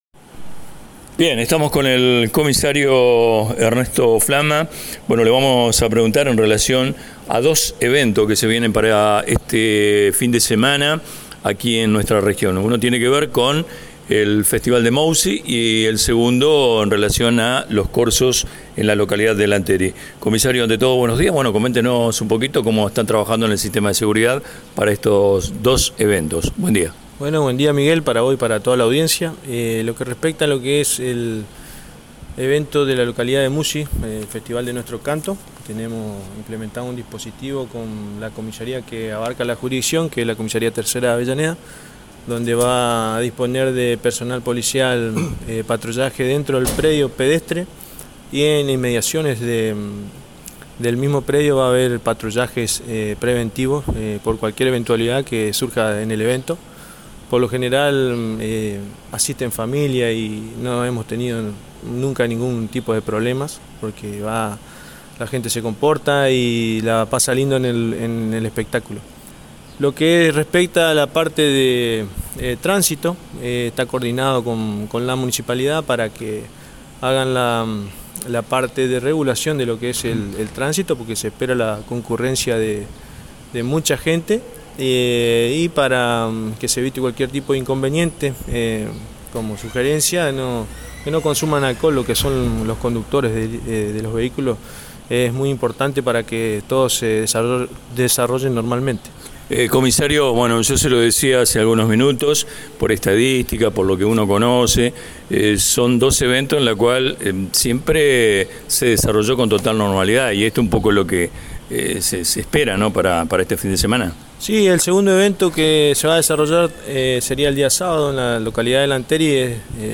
En el marco de estos preparativos, se llevó a cabo una entrevista